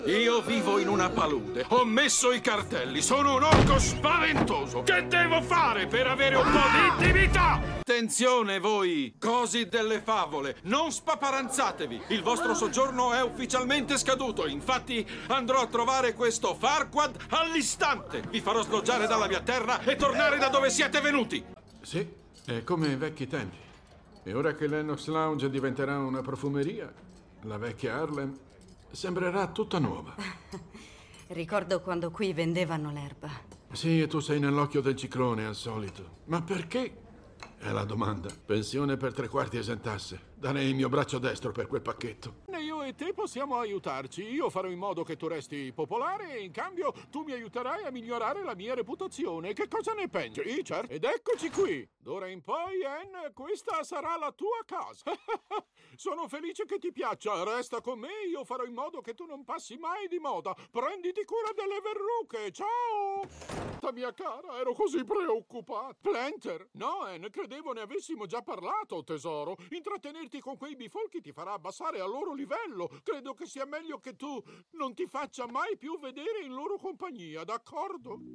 voce di Renato Cecchetto nel film d'animazione "Shrek", in cui doppia Shrek, nel telefilm "Luke Cage", in cui doppia John Scurti, e nel cartone animato "Anfibia", in cui doppia il Sindaco Toadstool.